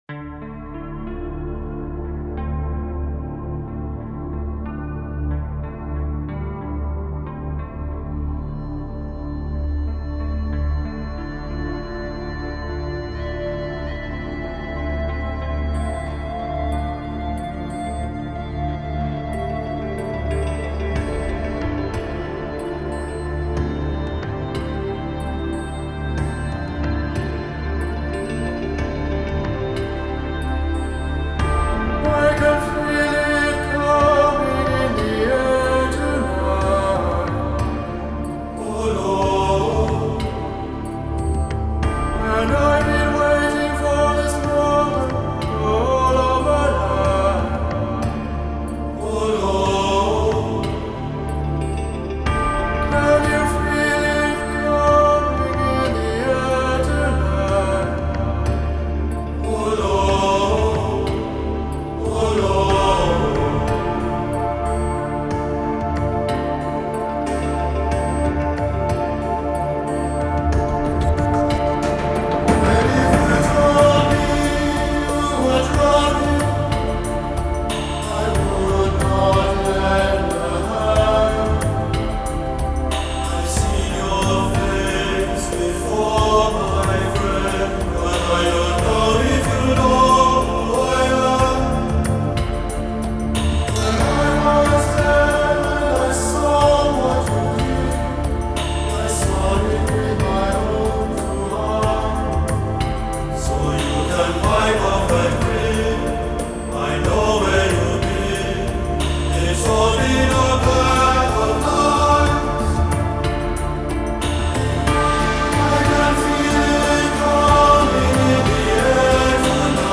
Genre: Pop, Chants